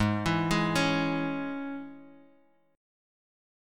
G#sus4#5 Chord